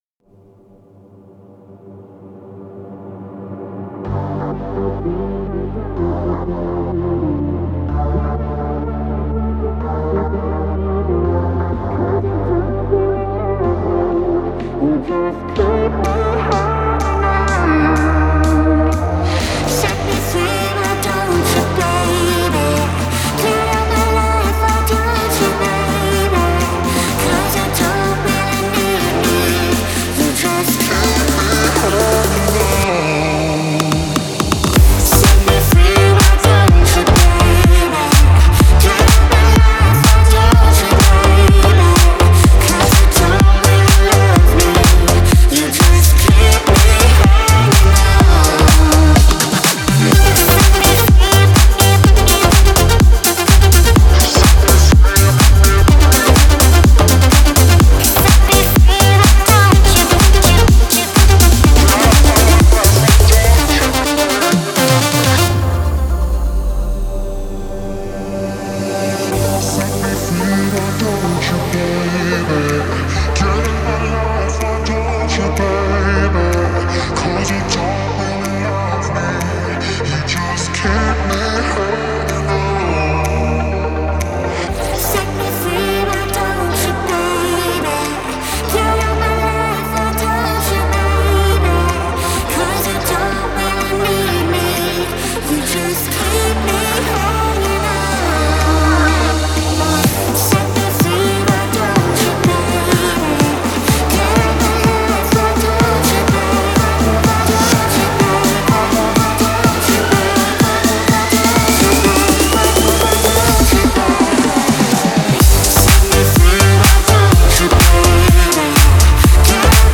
это зажигательная электронная композиция в жанре EDM